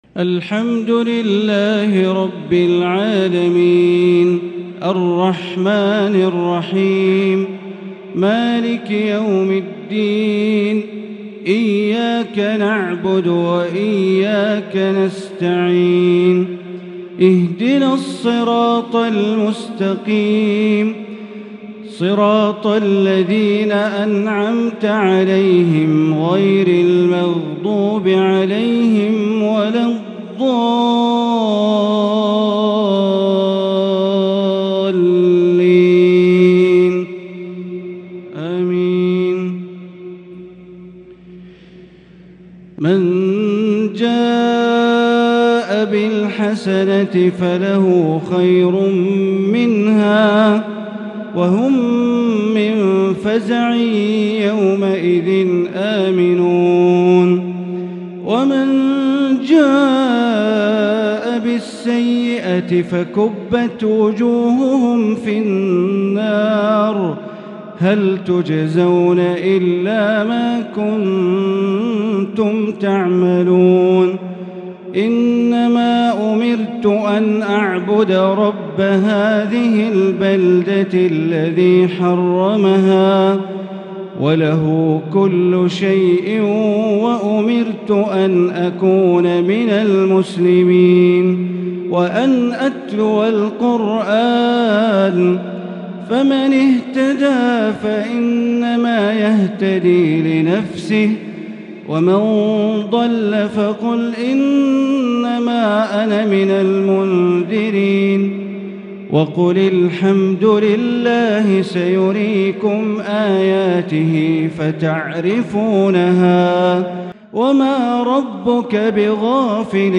عشاء الثلاثاء 4 رمضان 1443هـ من سورتي النمل و القصص | Isha prayer Surah An-naml and al- al-Qasas 5-4-2022 > 1443 🕋 > الفروض - تلاوات الحرمين